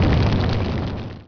flame_off.wav